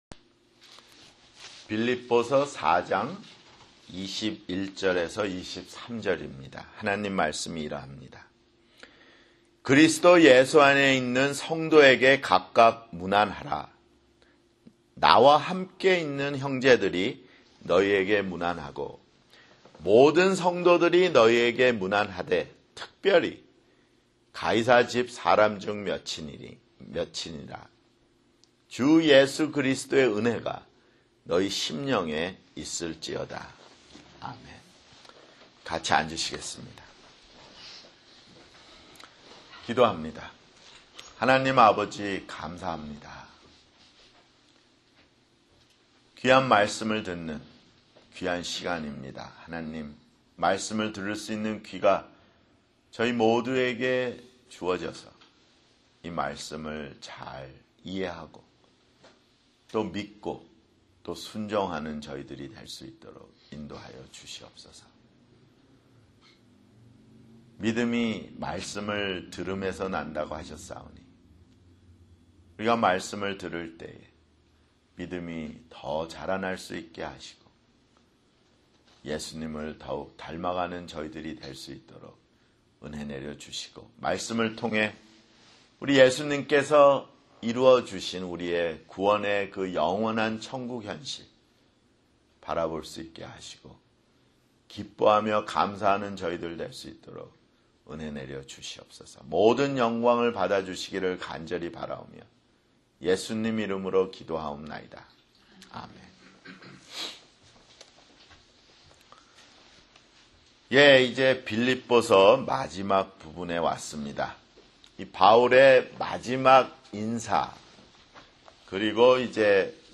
[주일설교] 빌립보서 (62)